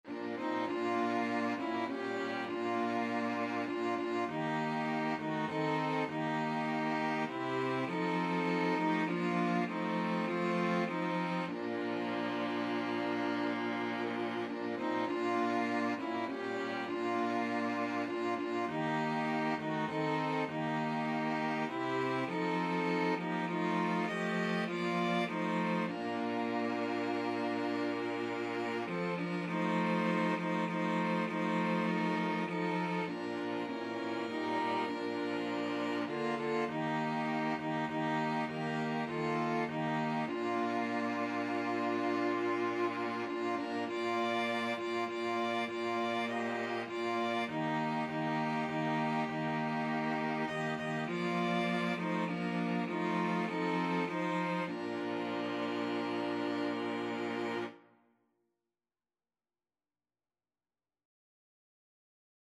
Violin 1Violin 2ViolaCello
6/8 (View more 6/8 Music)
Classical (View more Classical String Quartet Music)